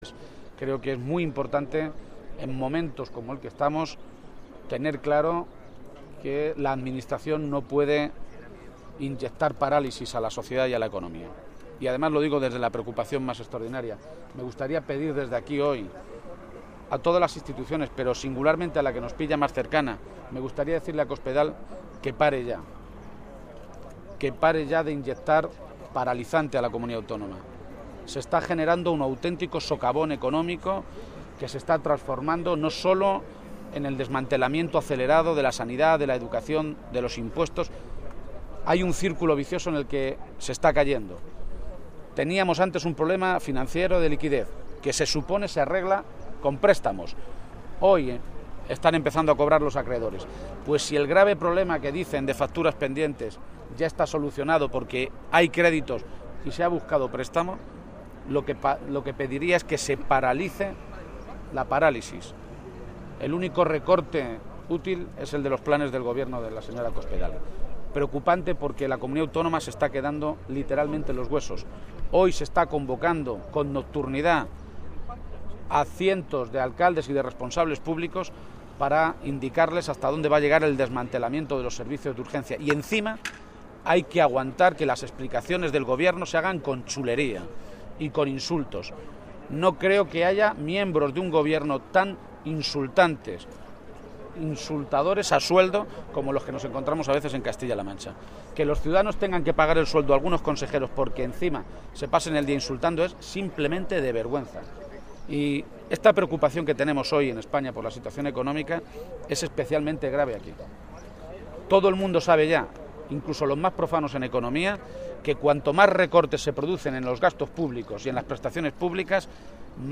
Emiliano García-Page, Secretario General del PSOE de Castilla-La Mancha
Cortes de audio de la rueda de prensa